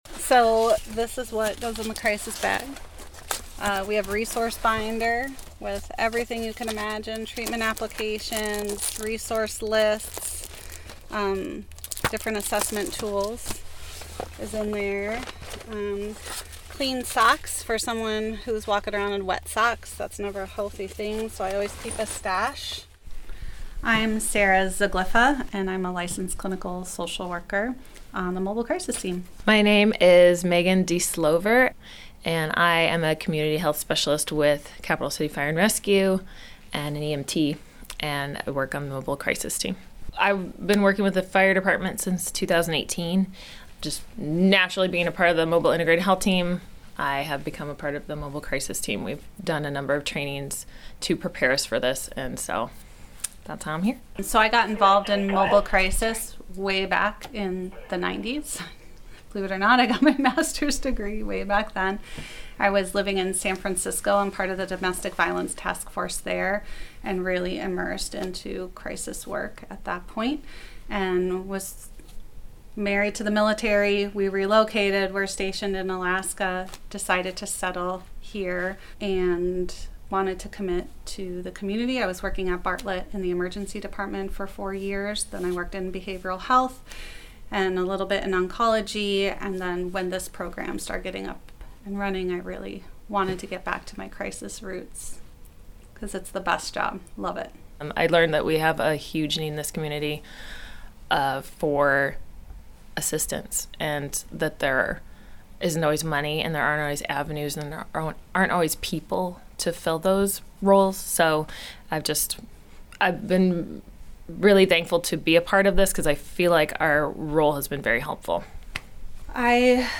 This is Tongass Voices, a series from KTOO sharing weekly perspectives from the homelands of the Áak’w Kwáan and beyond.